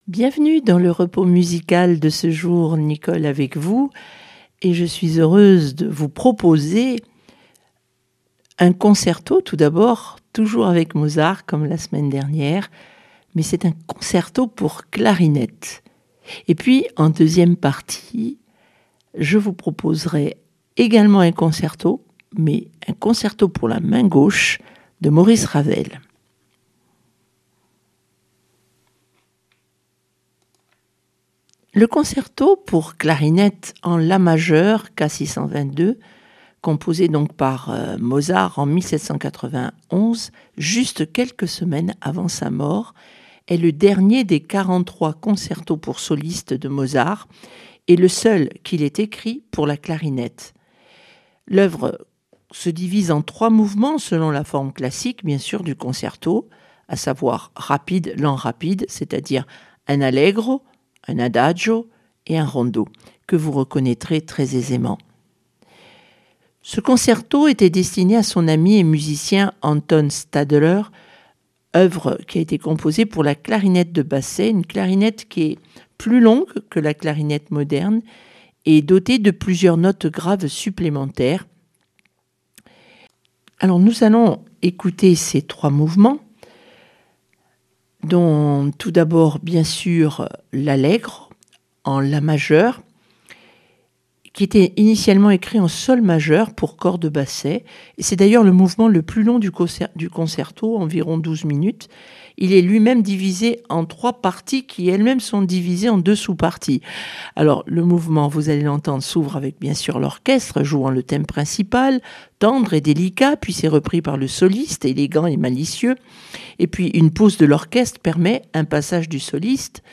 concerto clarinette Mozart Ravel concerto pour main gauche